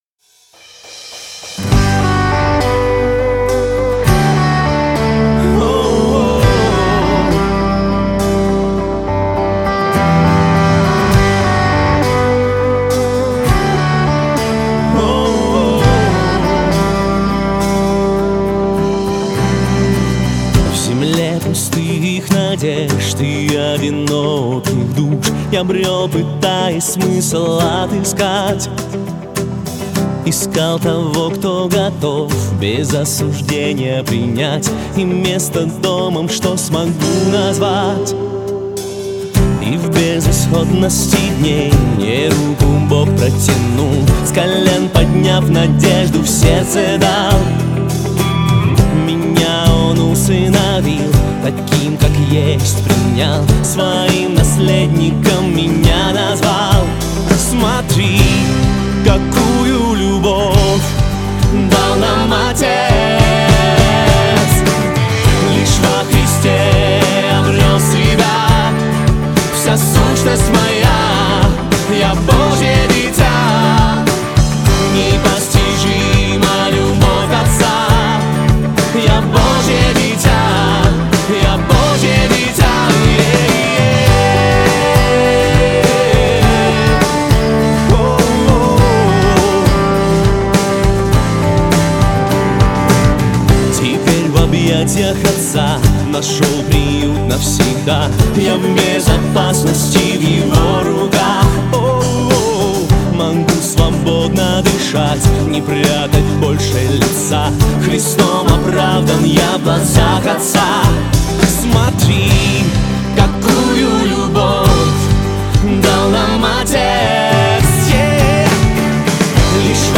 1761 просмотр 711 прослушиваний 223 скачивания BPM: 102